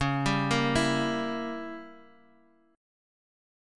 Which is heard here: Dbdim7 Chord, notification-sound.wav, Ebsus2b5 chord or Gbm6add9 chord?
Dbdim7 Chord